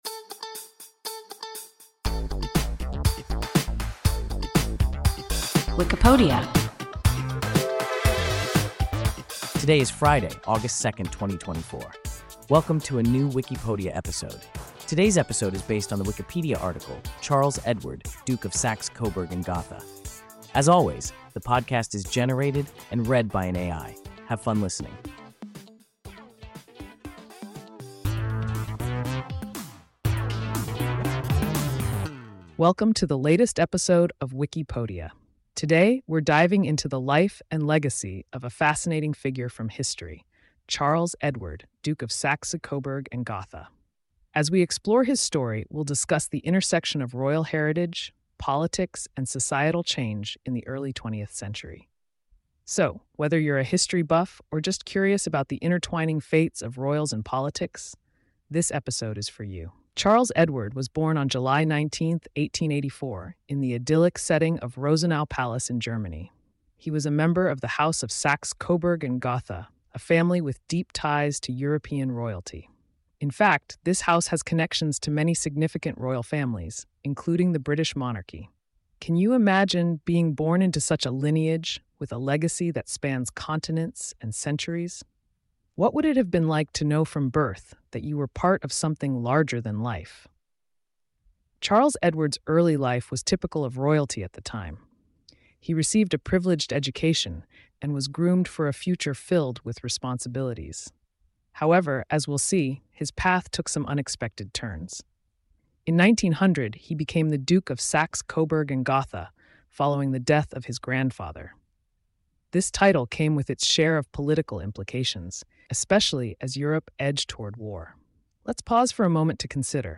Charles Edward, Duke of Saxe-Coburg and Gotha – WIKIPODIA – ein KI Podcast